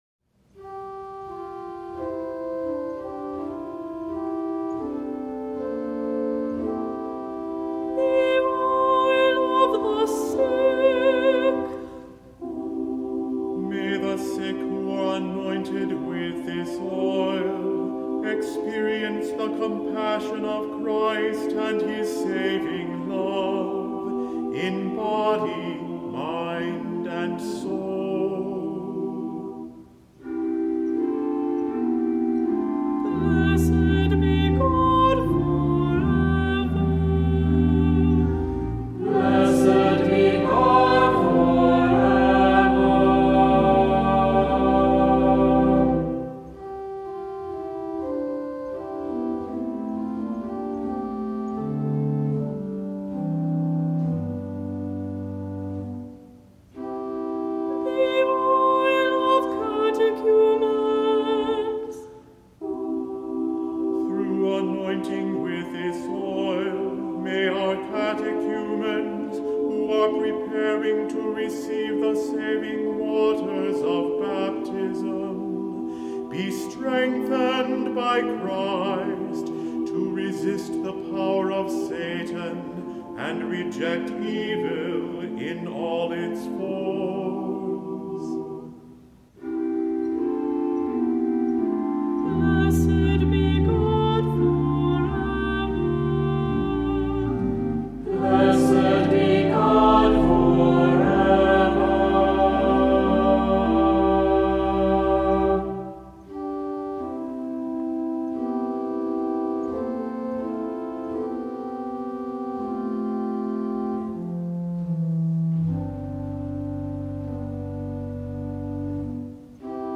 Voicing: SATB; Priest; Narrator; Cantor; Assembly